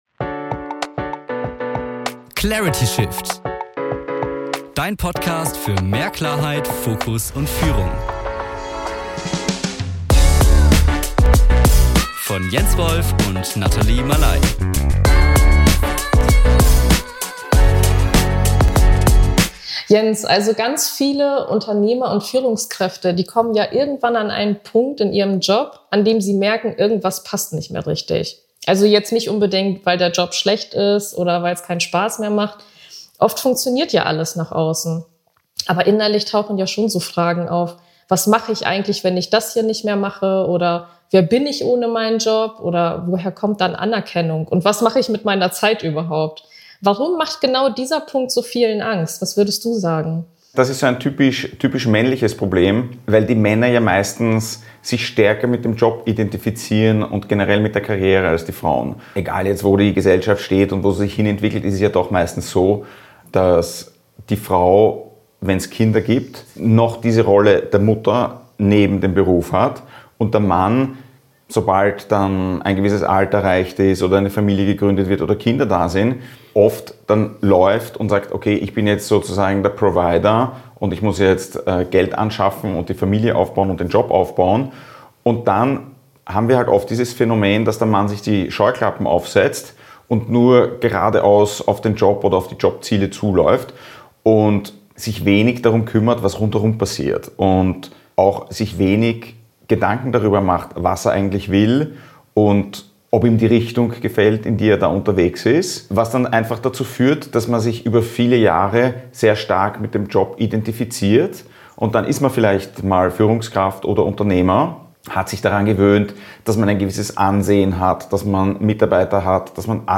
In diesem Gespräch geht es um Loslassen. Nicht theoretisch, sondern ganz konkret im Alltag von Unternehmern und Menschen mit Verantwortung.